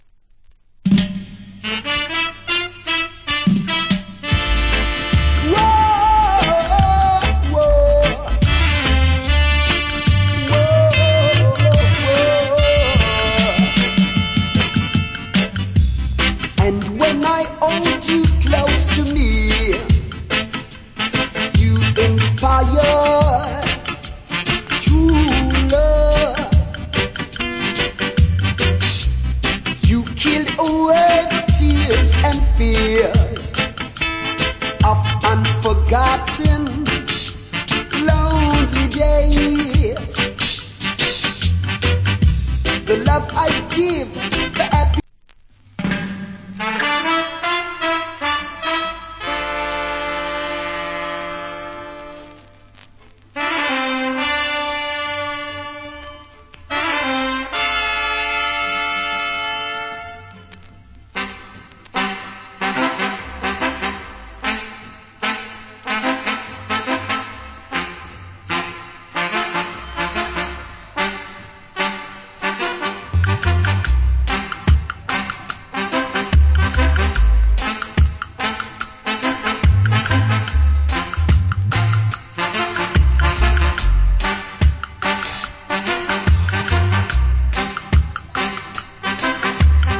Killer Roots Rock Vocal.